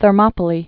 (thər-mŏpə-lē)